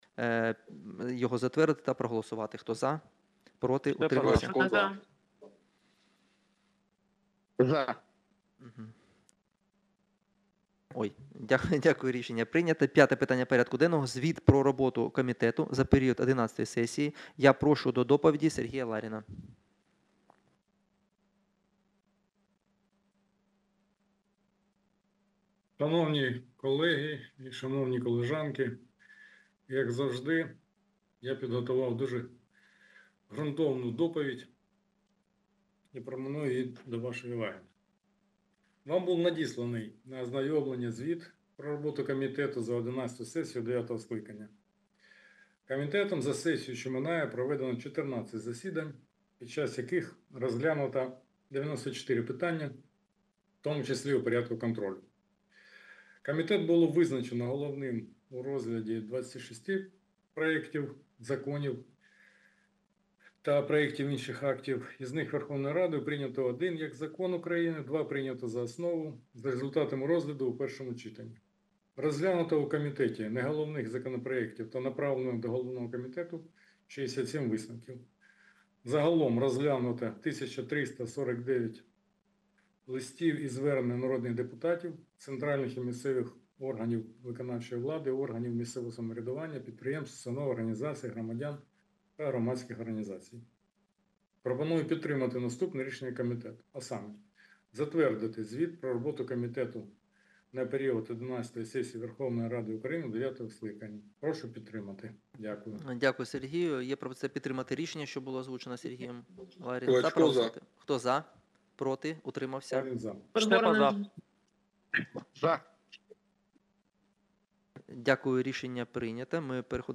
Аудіозапис засідання Комітету від 14.08.2024